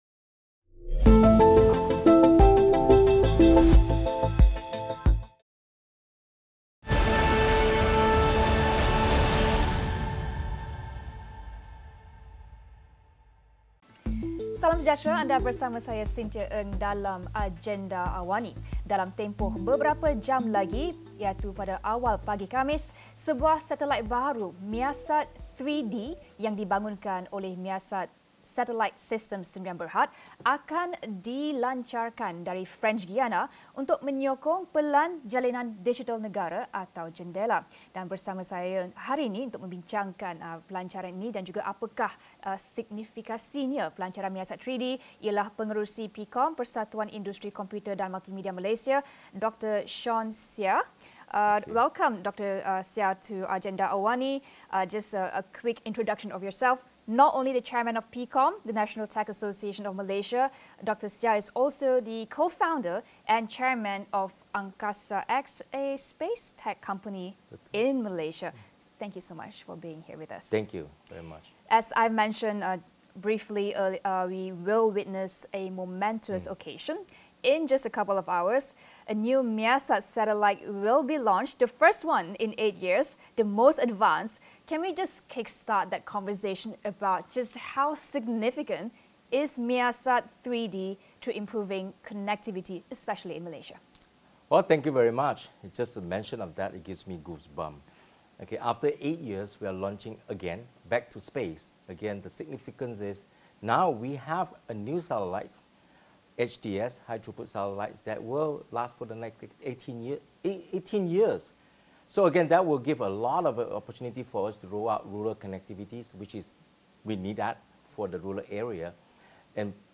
Apa signifikannya pelancaran satelit Measat-3d kepada rakyat, dan bagaimana ia boleh merapatkan jurang capaian jalur lebar terutamanya di kawasan pedalaman? Diskusi 9 malam